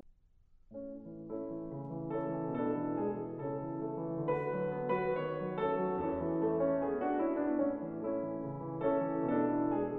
For my dataset, I chose two piano composers of different style, J.S. Bach and F. Schubert.
Now, let’s have a look at Schubert, first the unperturbed original: